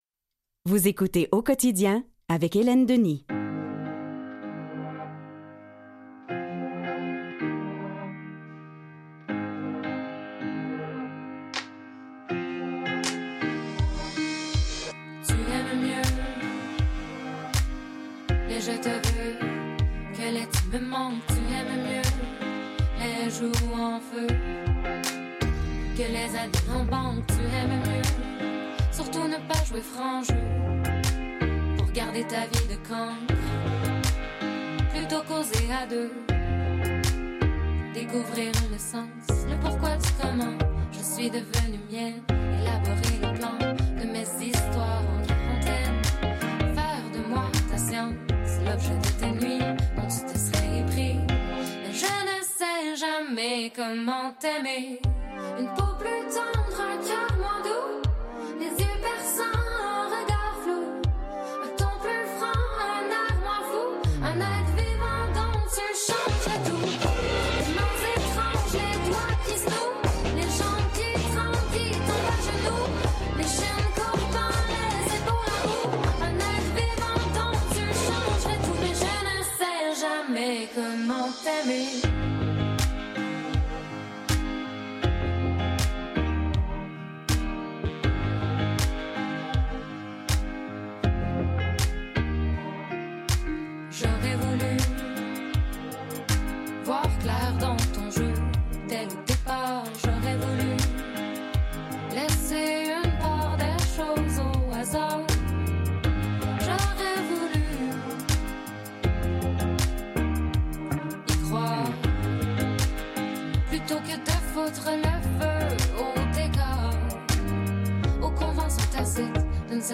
En direct de nos studios